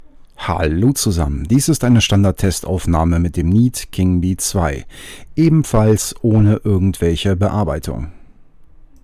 Hier eine Aufnahme des Neat King Bee 2 ohne Bearbeitung:
Keinerlei Hintergrundgeräusche werden störend mit aufgenommen, wobei es schon sehr ruhig war als die Aufnahmen gemacht worden sind.